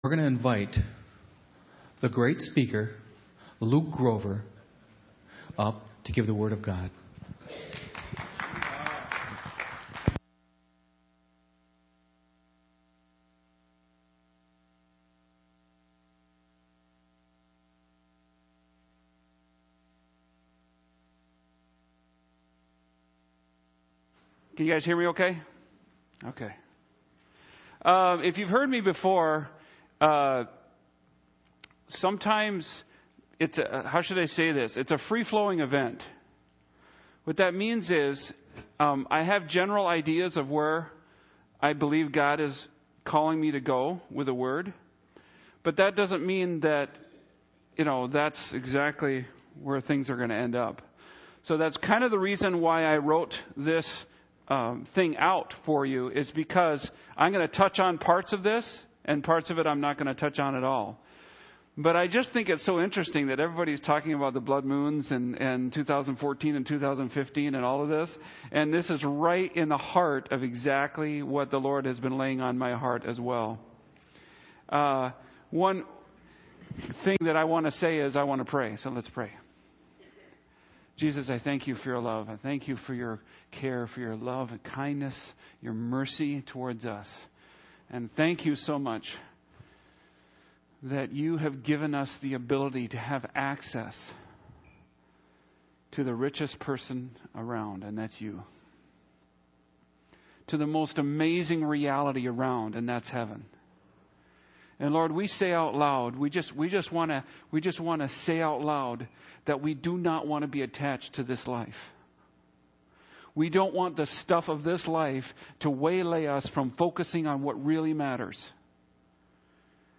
Matthew 23-26 Download the sermon outline